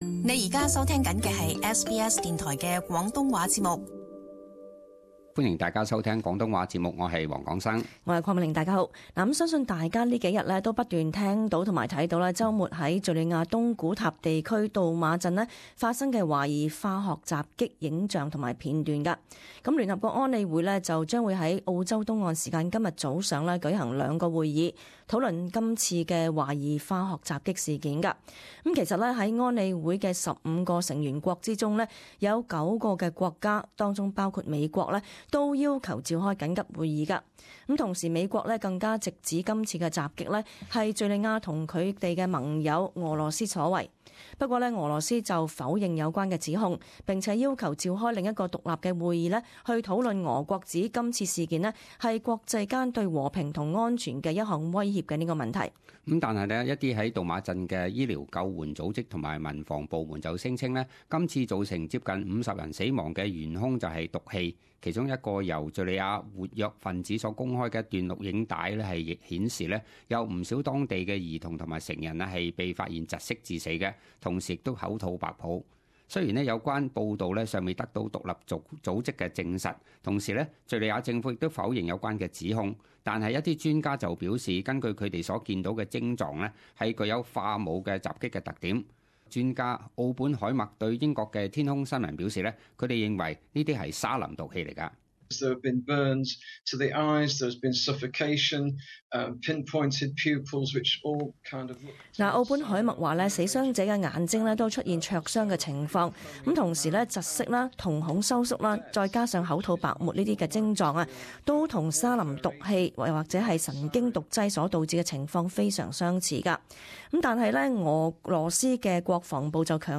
【时事报导】：敍国东古塔疑遭化武袭击多人死伤